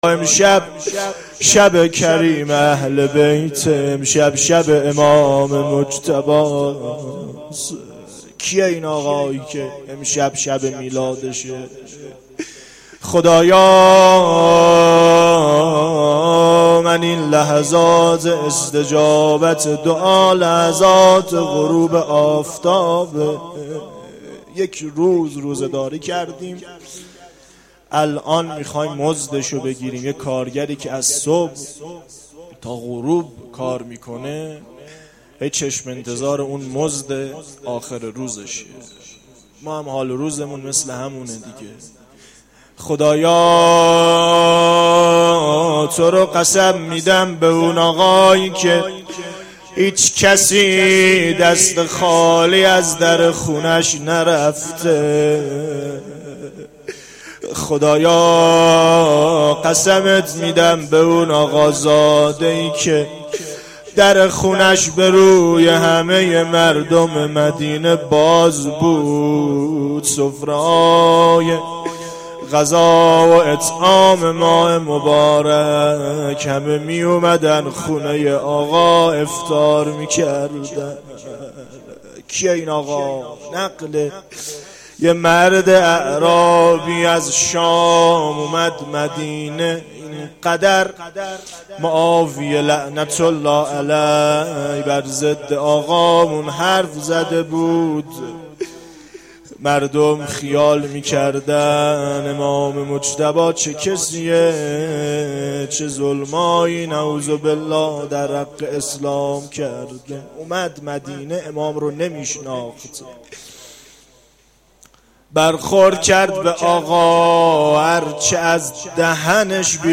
روضه2
مداحی
مناجات-دوم.mp3